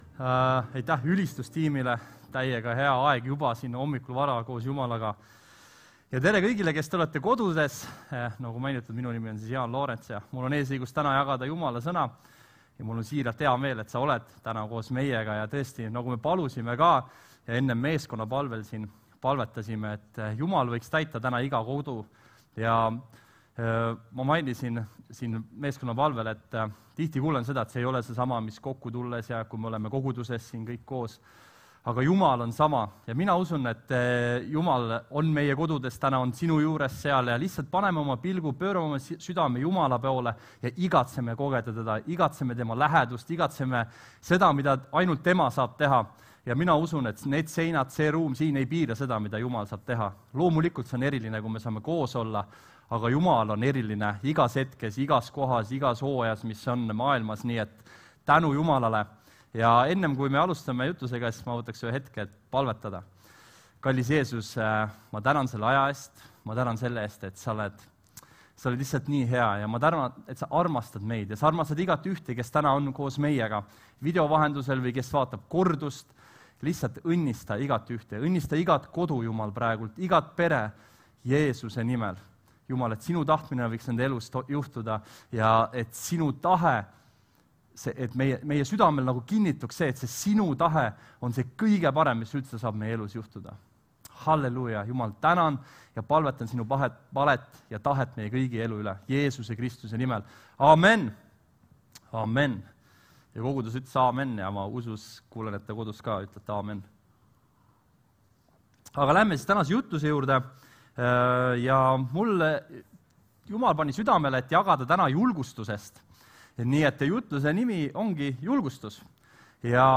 Jutlused - EKNK Toompea kogudus